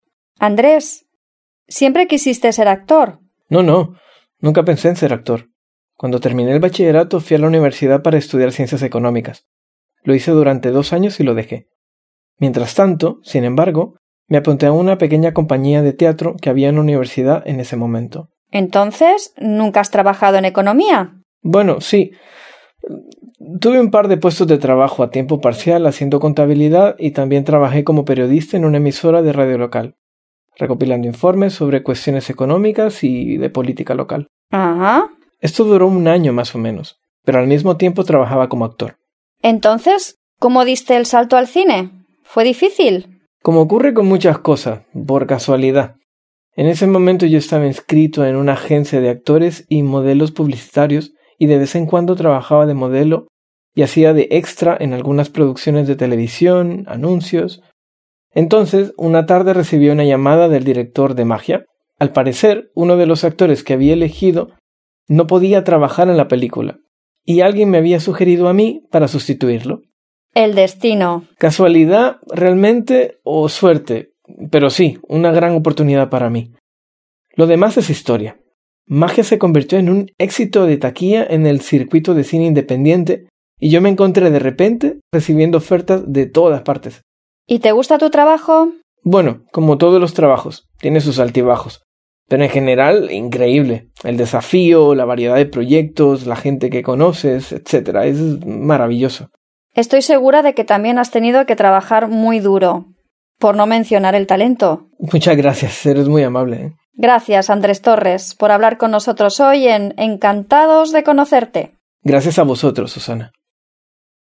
Escuche una entrevista con un actor y decida si las afirmaciones son verdaderas o falsas de acuerdo con lo que se dice en la conversación.